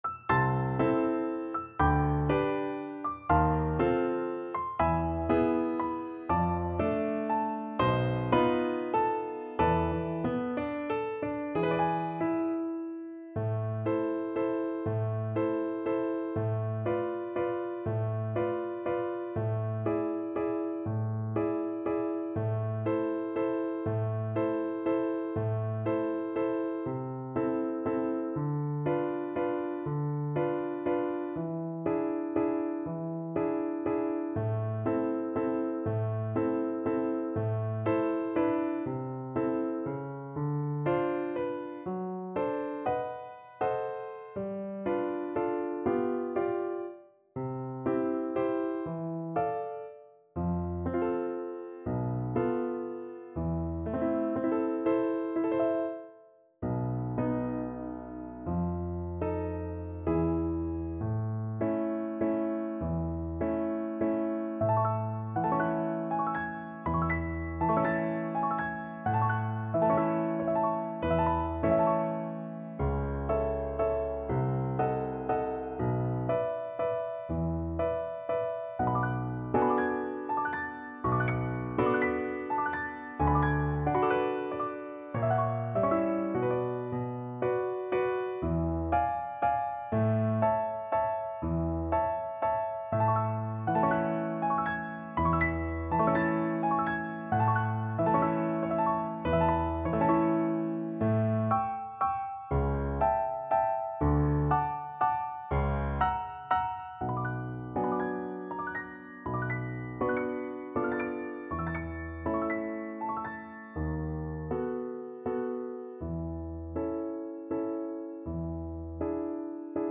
~ = 120 Lento
3/4 (View more 3/4 Music)
Classical (View more Classical Viola Music)